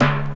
• Classic Reggae Tom Drum Sound D Key 37.wav
Royality free tom single hit tuned to the D note. Loudest frequency: 498Hz
classic-reggae-tom-drum-sound-d-key-37-sGr.wav